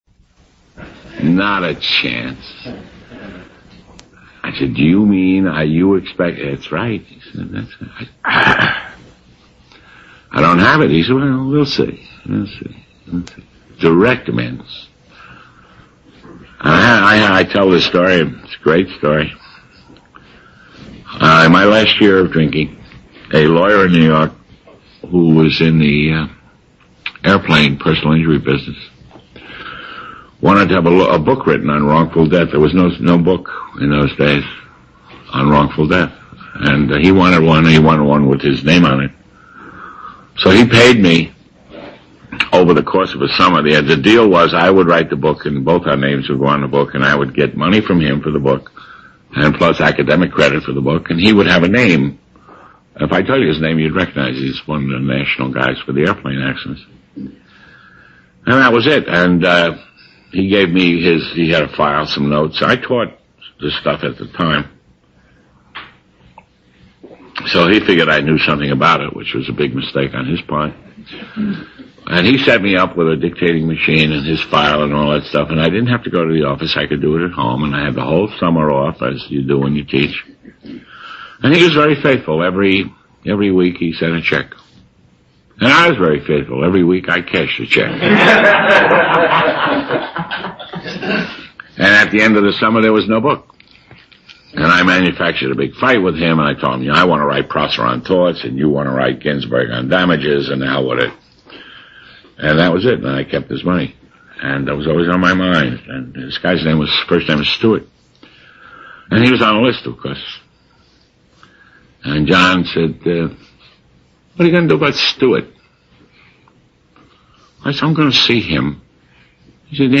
Grant's Pass OR
Speaker Tape